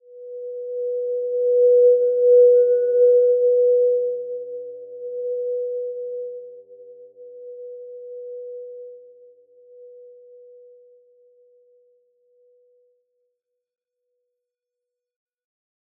Simple-Glow-B4-f.wav